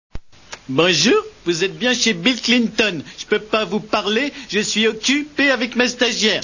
R�pondeur Homer Simpson